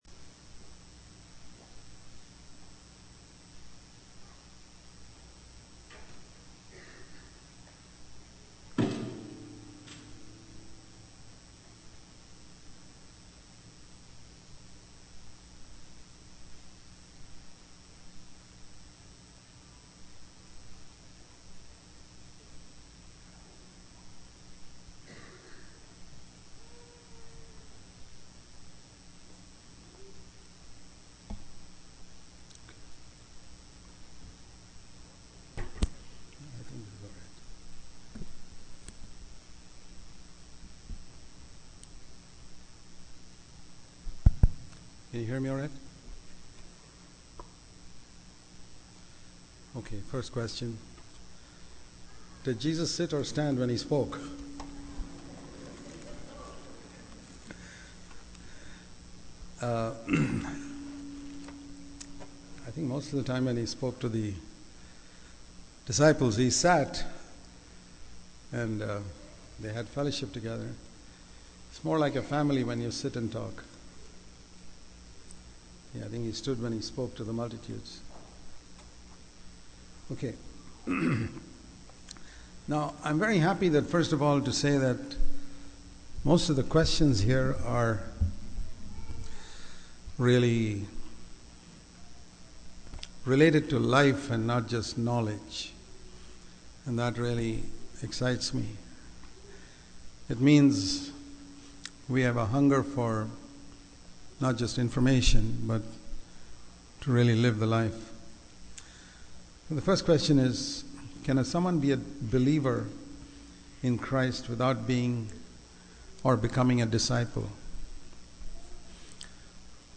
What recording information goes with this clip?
Q &A Session 1 - Atlanta Conference 2014 | cfcindia, Bangalore